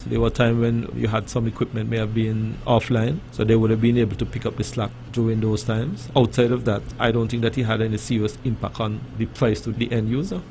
During Tuesday evening’s edition of the Let’s Talk Programme, a discussion on the status of renewable energy on Nevis came to the fore.